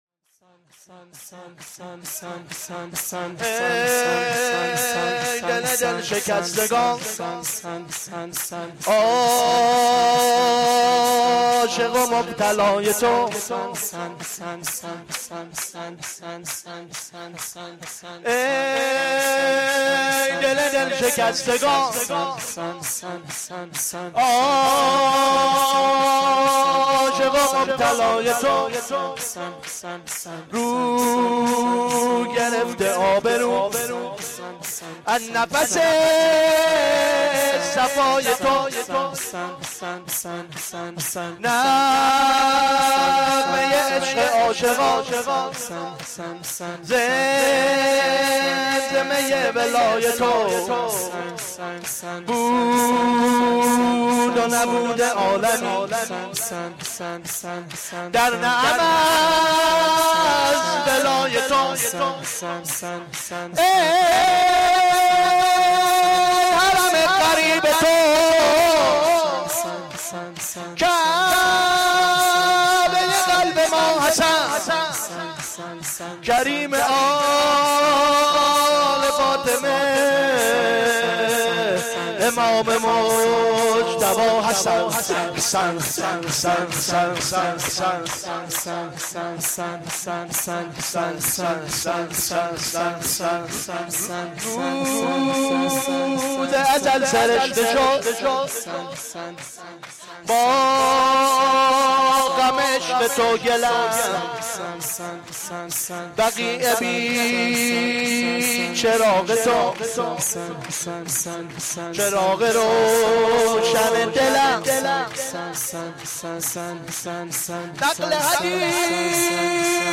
شور
shoor3-Rozatol-Abbas.Milad-Emam-Hasan.mp3